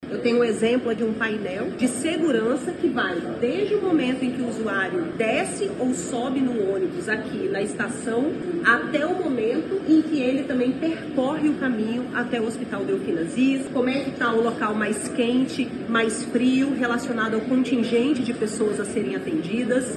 A visita permitiu aos gestores conhecerem de perto soluções inovadoras adotadas no monitoramento da unidade, como o sistema que acompanha desde o deslocamento dos pacientes até a distribuição de refeições, como explica a secretária de saúde do estado, Nayara Maksoud.
Sonora-1-Nayara-Maksoud.mp3